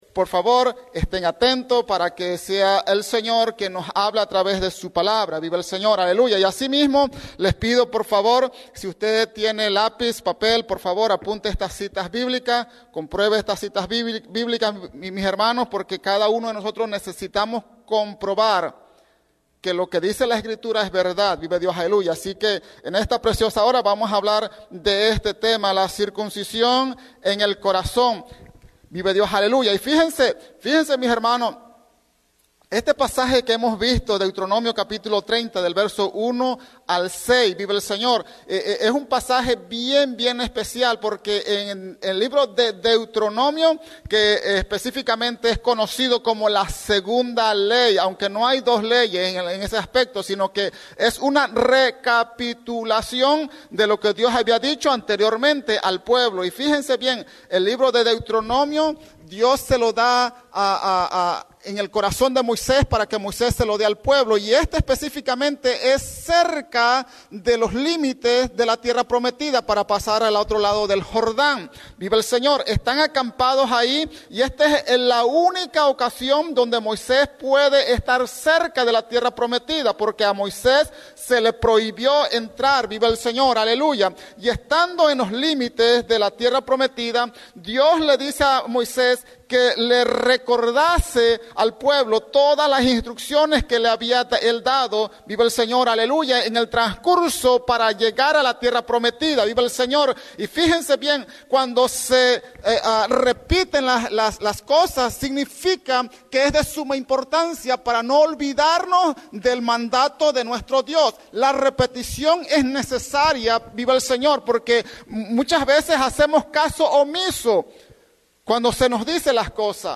en la Iglesia Misión Evangélica en Norristown, PA